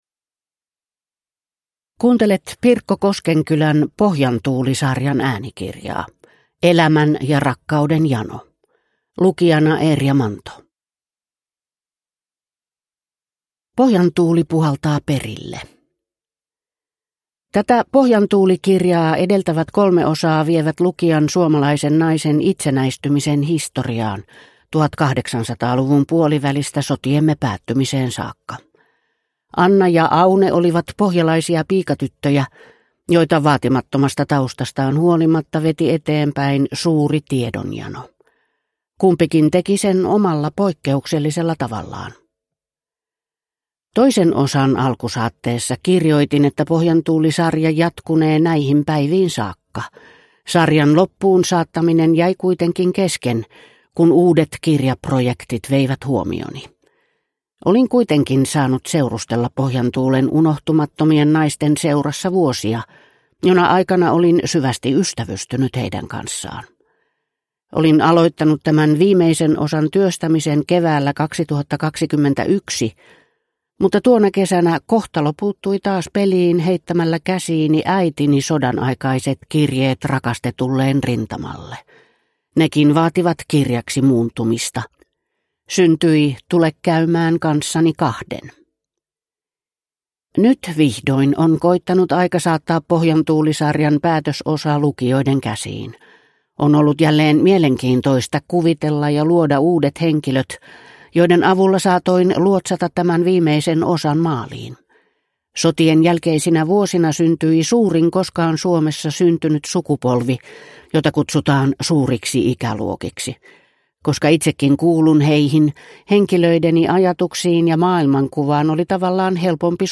Pohjantuuli (ljudbok) av Pirkko Koskenkylä